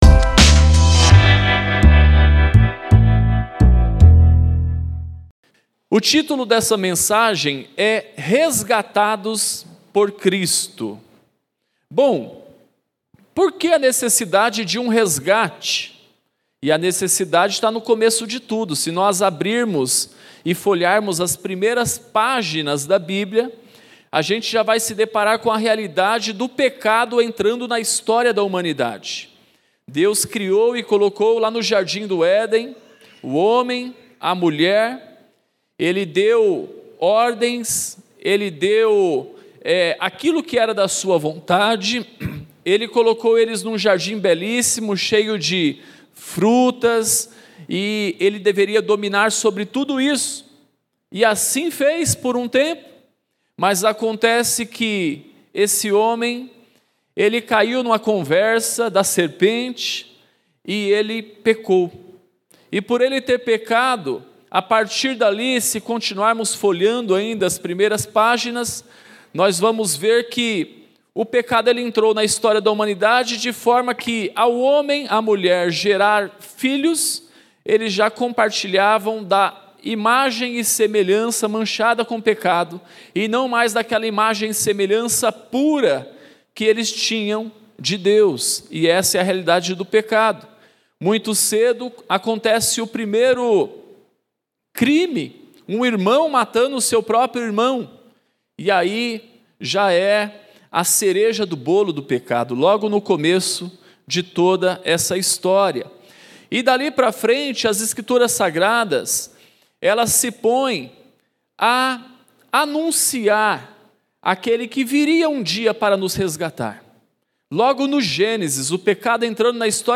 Culto da Família | PIB Central